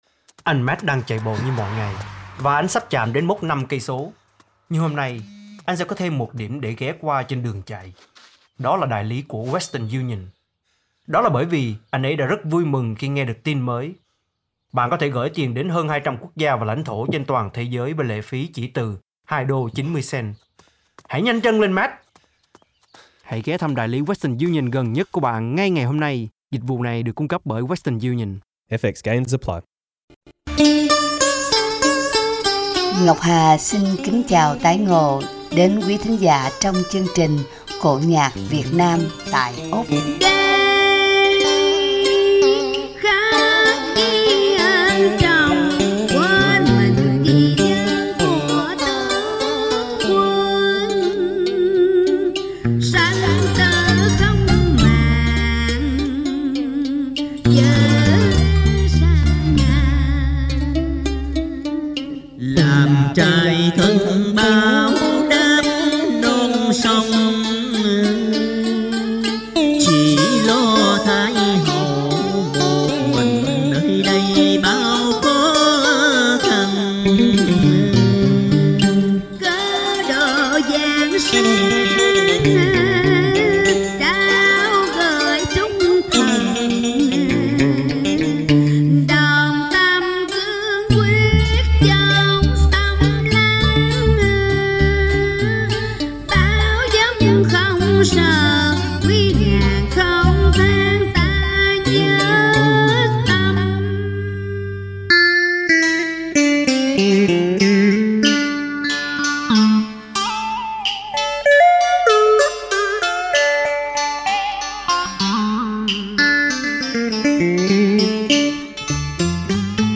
Cổ nhạc Việt Nam tại Úc: Thái hậu Dương Vân Nga